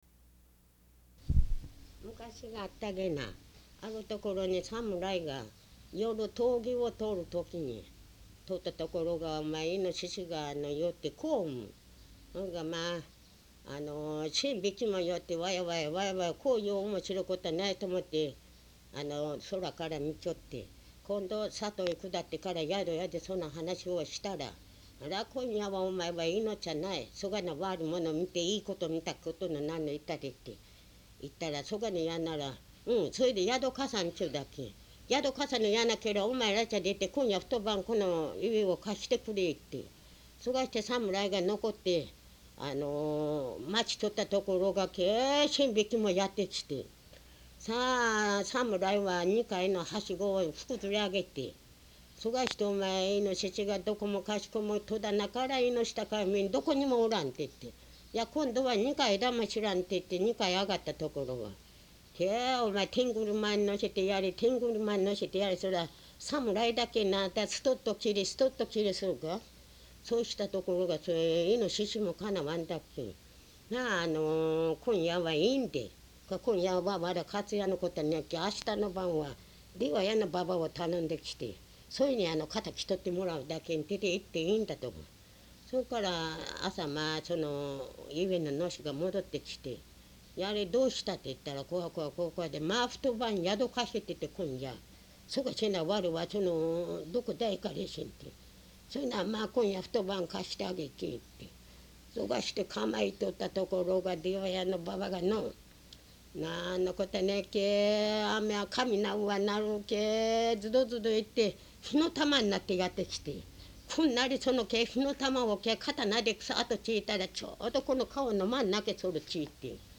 出葉屋の婆（知夫村）
実に元気のよい語り口で、聞いている者の関心をそらさない巧みなものであった。稲田浩二『日本昔話通観』によれば、「むかし語り」の「厄難克服」の中の「賢さと愚かさ」に「鍛冶屋の婆」として分類されている有名な昔話の仲間なのである。